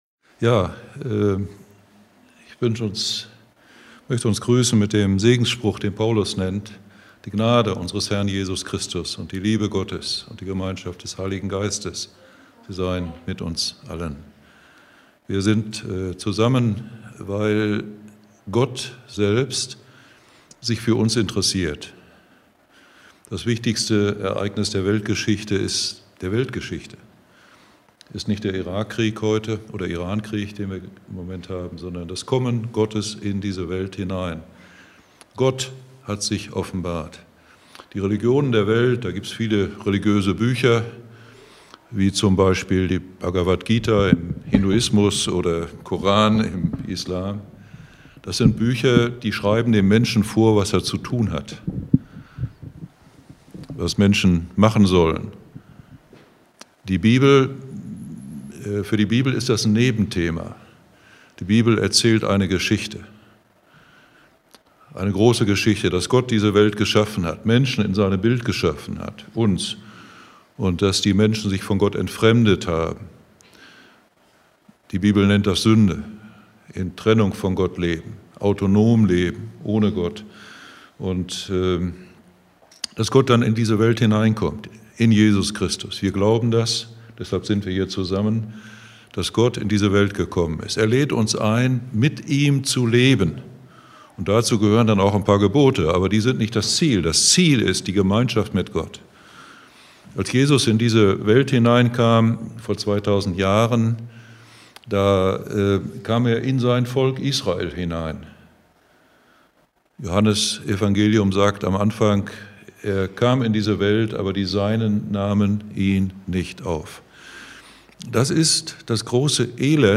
Im ersten Teil entfaltet die Predigt die Bedeutung der sieben „Zeichen“ im Johannesevangelium (Wasser zu Wein, Heilungen, Sturmstillung, Auferweckung des Lazarus u.a.).